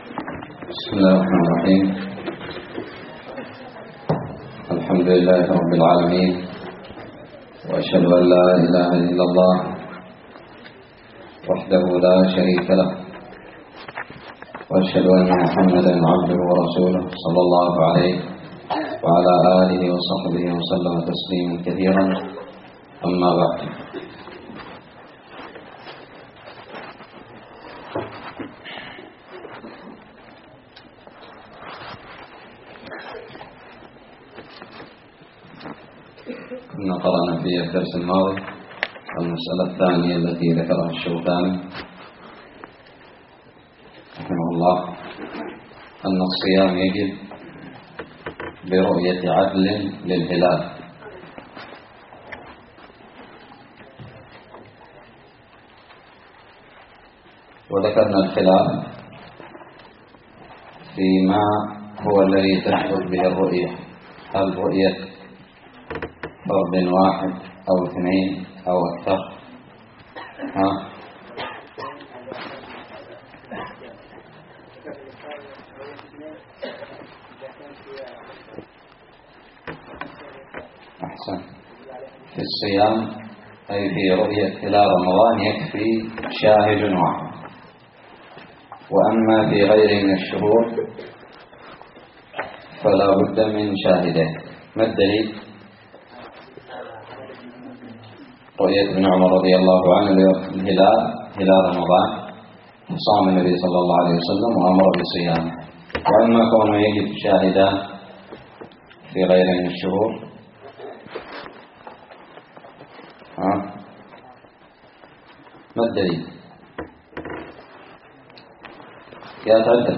الدرس الرابع من كتاب الصيام من الدراري
ألقيت بدار الحديث السلفية للعلوم الشرعية بالضالع